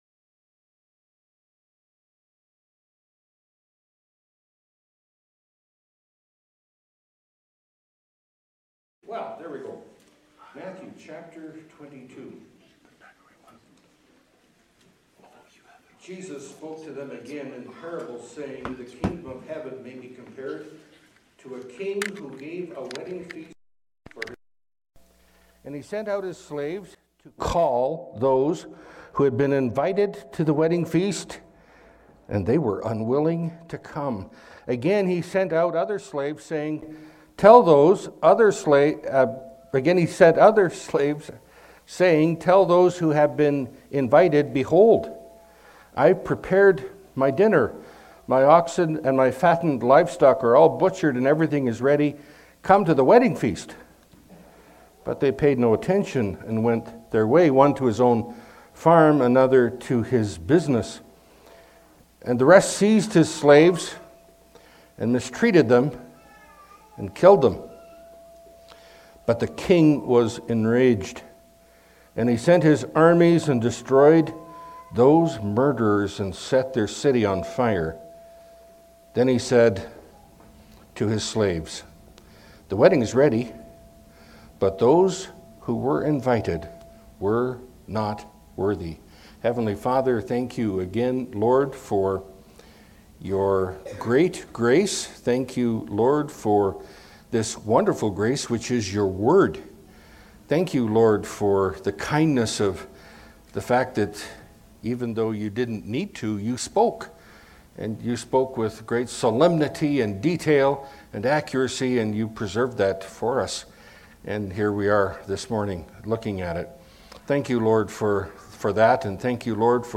Category: Pulpit Sermons